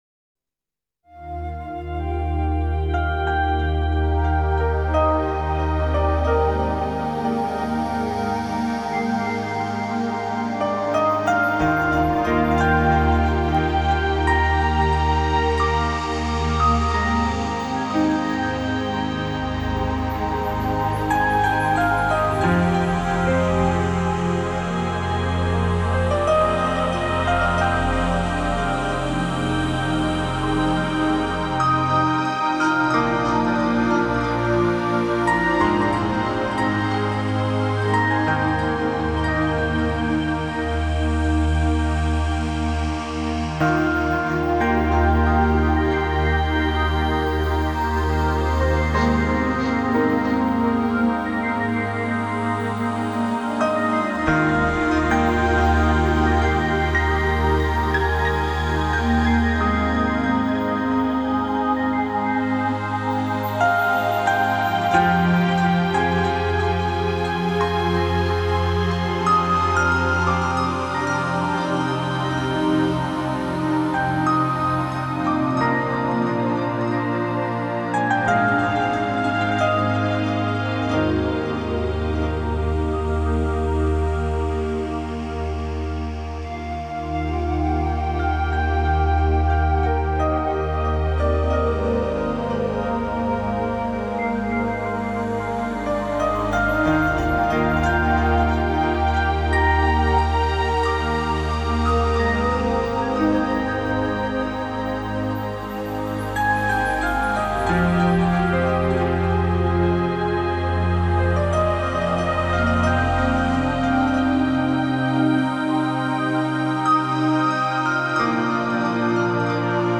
音乐流派；新世纪音乐 (New Age) / 环境音乐 (Ambient Music)
歌曲风格：轻音乐 (Easy Listening) / 纯音乐 (Pure Music)
新世纪发烧EQ音乐 旋律优美·舒缓神经·灵性创作